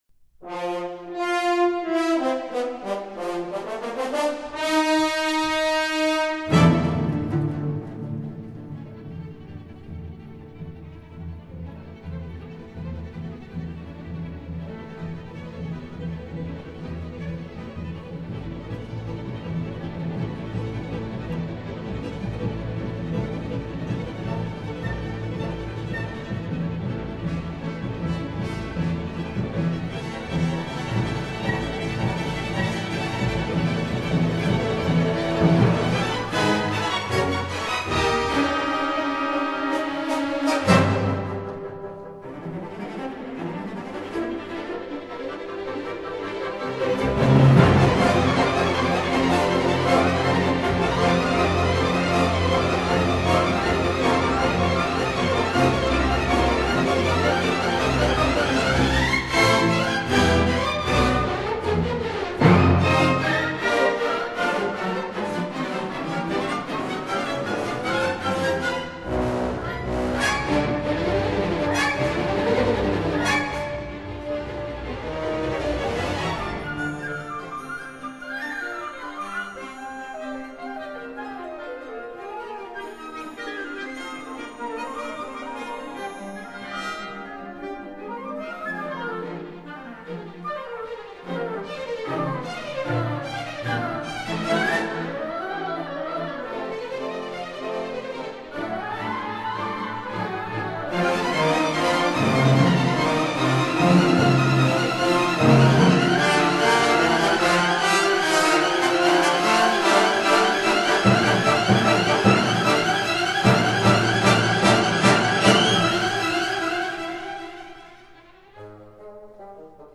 conductor